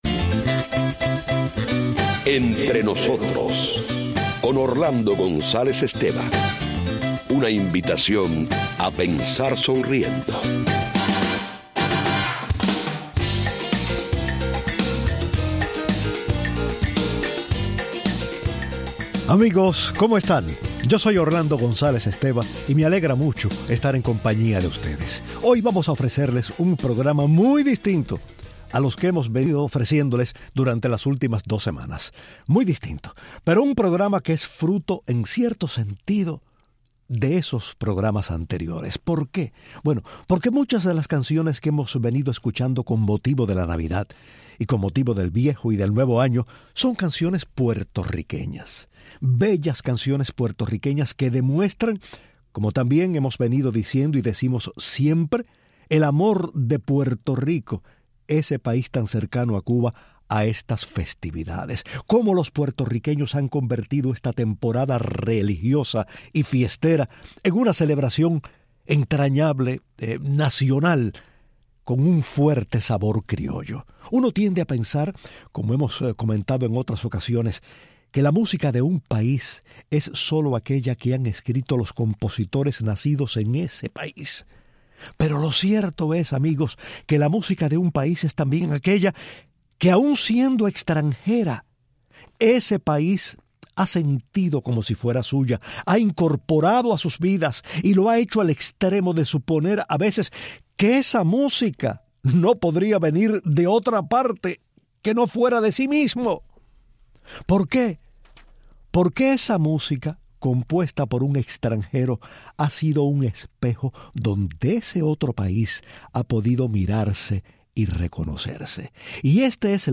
En 1971 la gran cantante participa en un programa televisivo del compositor puertorriqueño y lo complace interpretando, algunas canciones de la Vieja Trova cubana, canciones que jamás grabaría comercialmente. Hoy escuchamos una de sus interpretaciones y rendimos homenaje a Flores.